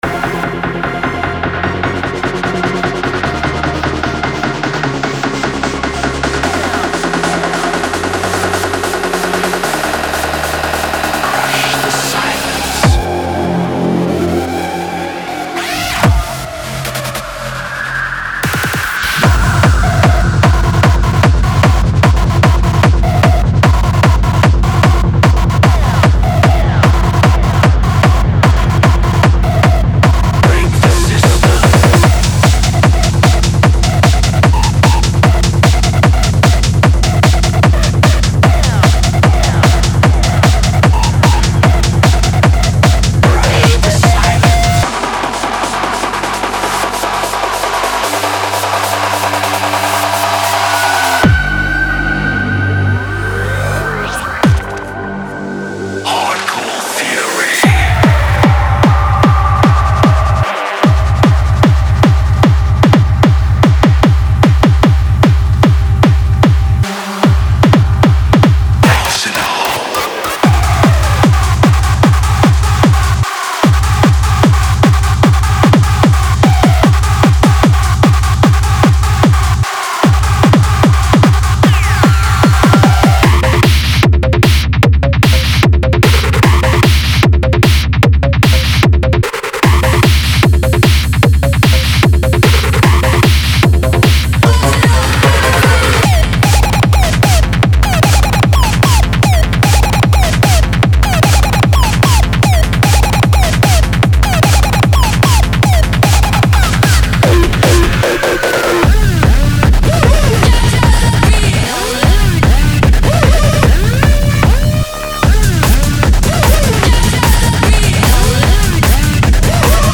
Genre:Hard Dance
デモサウンドはコチラ↓
150 Bpm